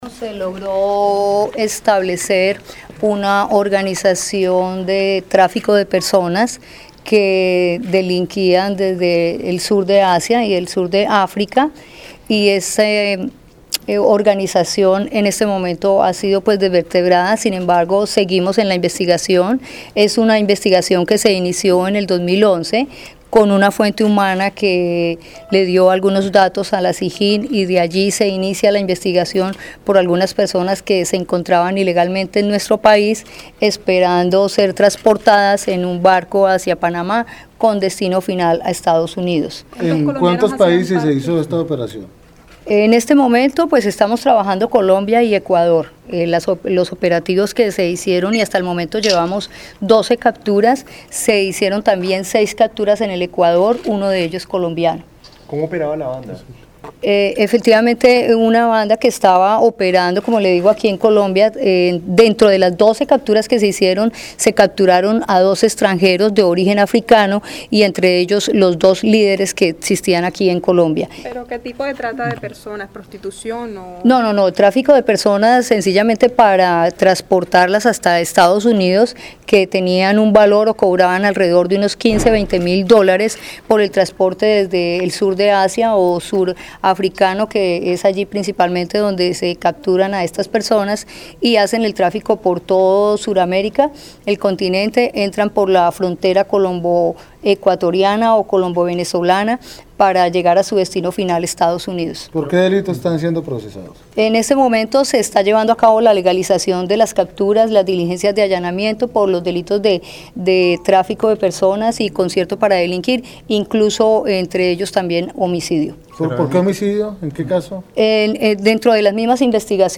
Rueda de prensa Directora Nacional CTI, Maritza Escobar Baquero
Lugar: Cartagena (Bolívar)